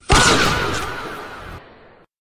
gunshot fahhhh Meme Sound Effect
gunshot fahhhh.mp3